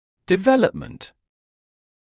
[dɪ’veləpmənt][ди’вэлэпмэнт]развитие, формирование, разработка